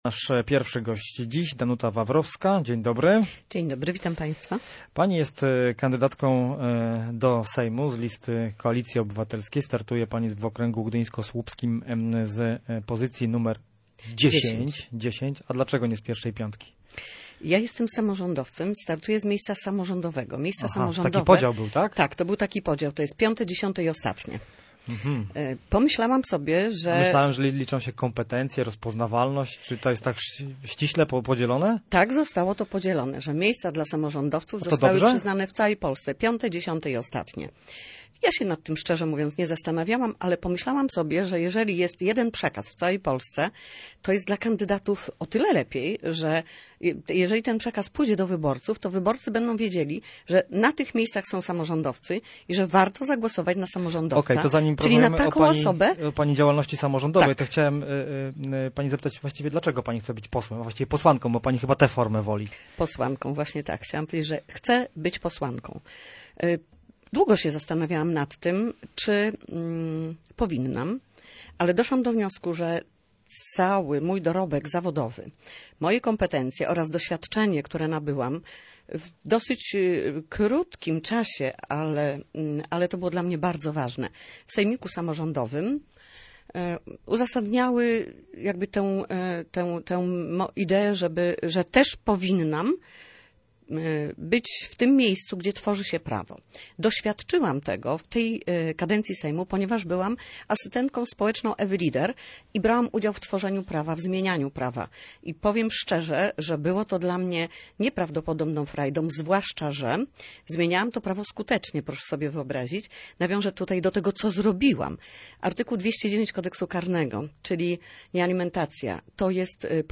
[ROZMOWA]